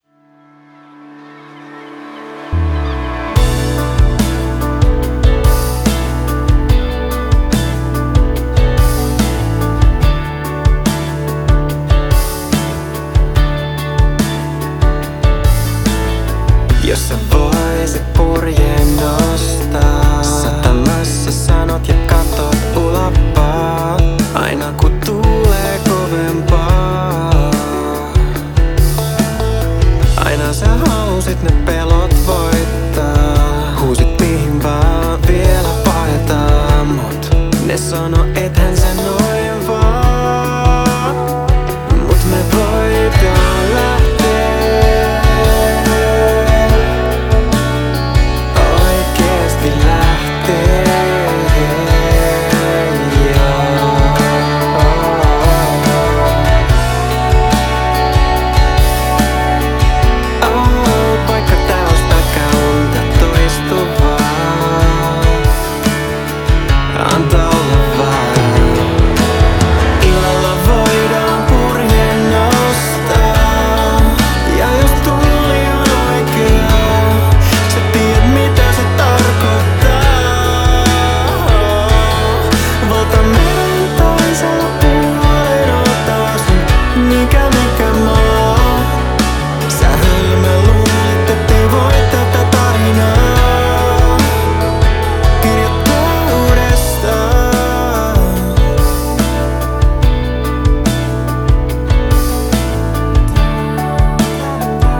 cover-kappaleita, omaa tuotantoa
viihdyttävä mies & kitara modernilla tatsilla & modernilla ohjelmistolla.